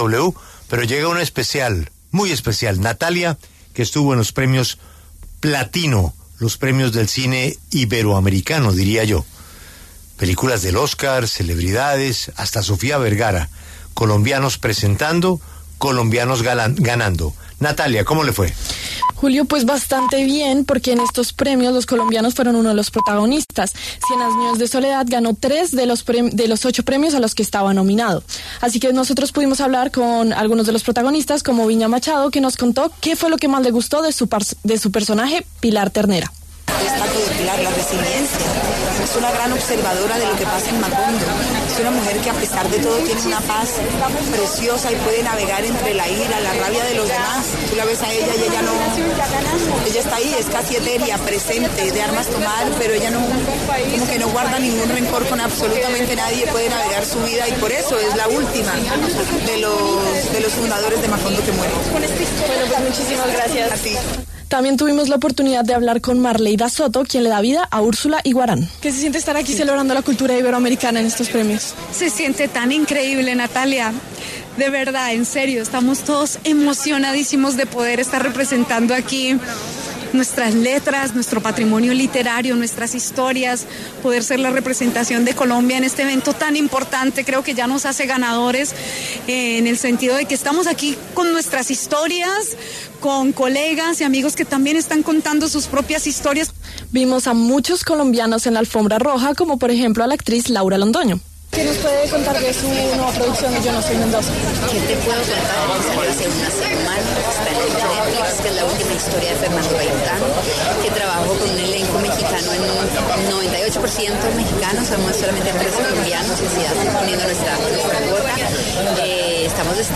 En la alfombra roja entrevistamos a algunos de los protagonistas de la serie colombiana: Viña Machado, Marleyda Soto, Édgar Vittorino y Ruggero Pasquarelli.